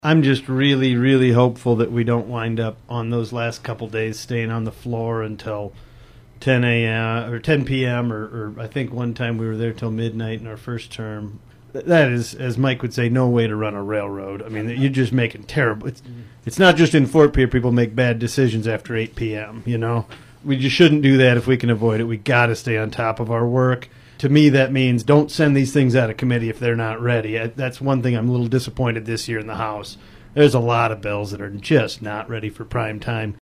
Representative Will Mortenson says late nights make for bad policy decisions…